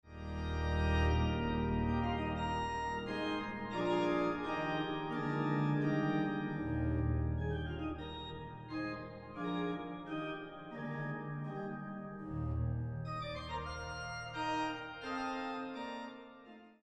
Builder: HinszType: Historic Dutch Baroque Organ
Location: Kampen, Netherlands